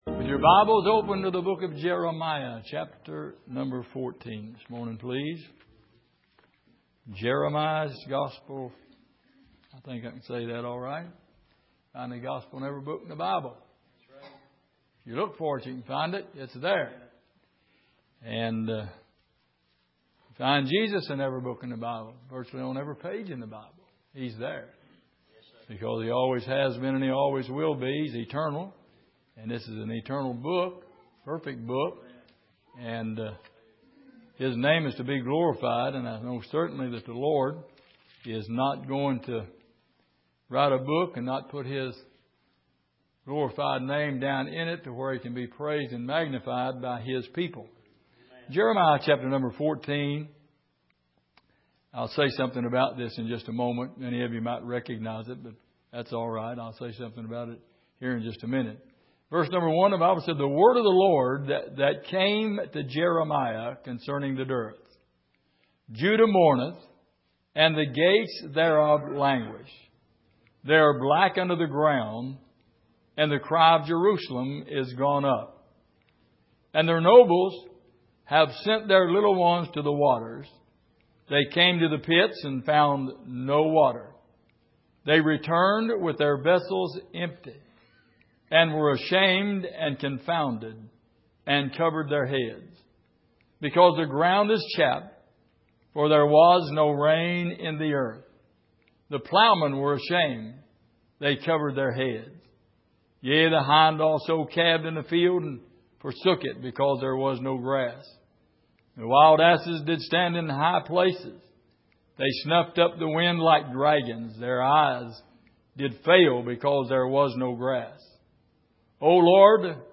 Jeremiah 14:1-4 Service: Sunday Morning Lord